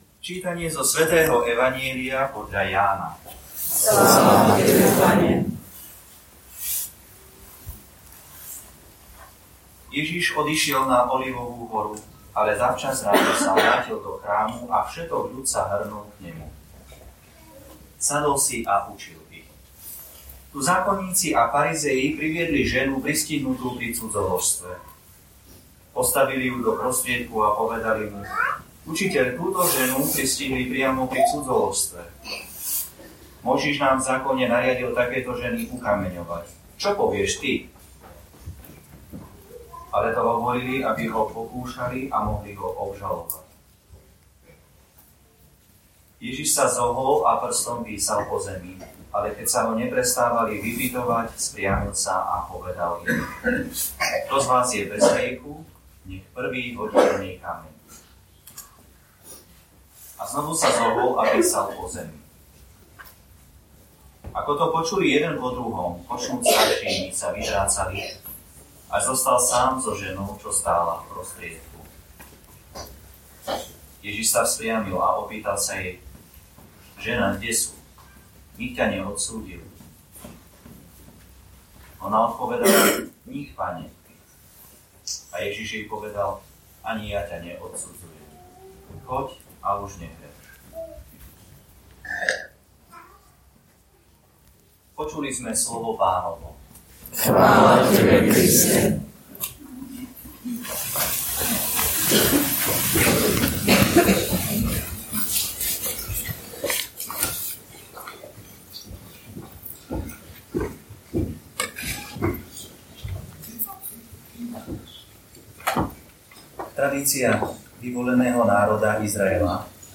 Podobne ako aj minulé roky, aj v tomto pôstnom období sa uskutočnila duchovná obnova pre slovenskú misiu v Zürichu.
Nahrávky jeho katechéz si môžete stiahnuť aj vypočuť tu: